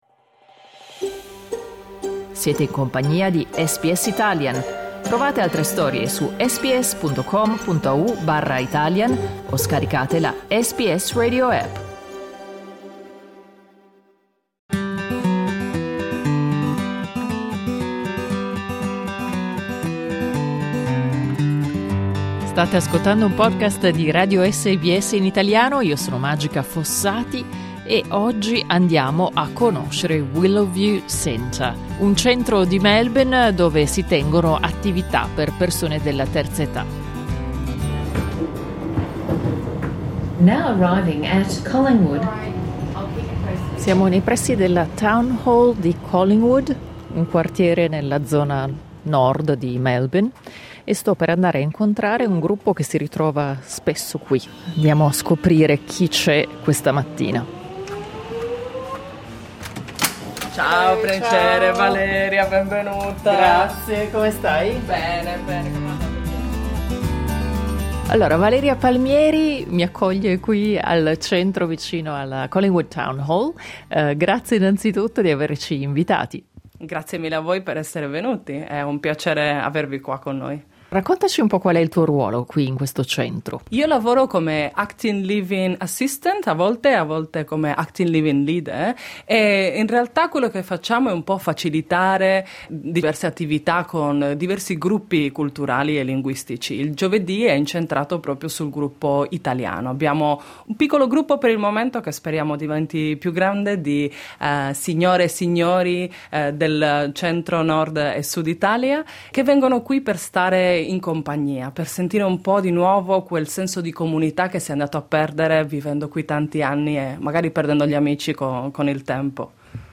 Clicca sul tasto "play" in alto per ascoltare il servizio integrale con tutte le interviste